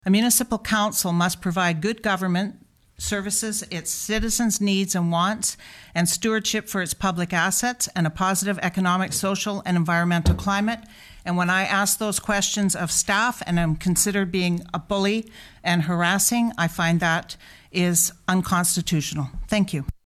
Benson took issue with the statement being read at the venue and made a statement of her own…..
Benson’s statement was met with applause from the gallery and then the meeting carried on as per the agenda.